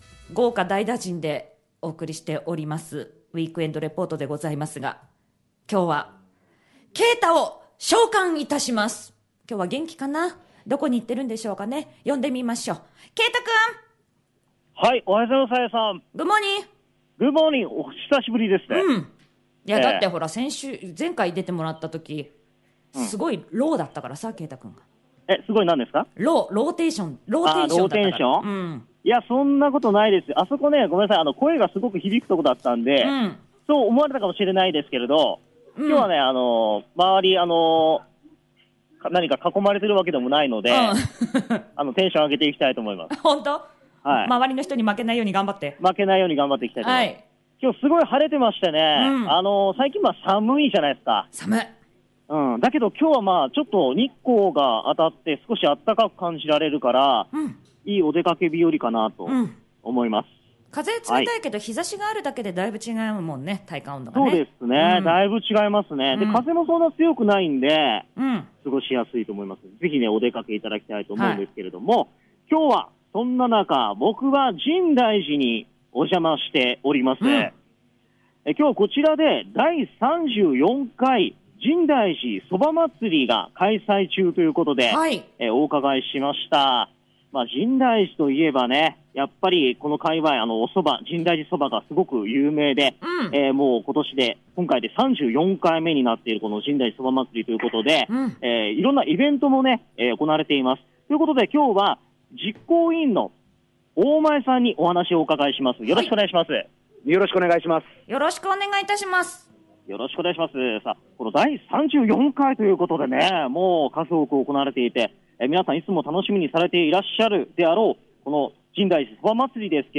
【第３４回深大寺そば祭り】
さて、今回は第３４回深大寺そば祭りに伺いました。